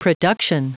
Prononciation du mot production en anglais (fichier audio)
Prononciation du mot : production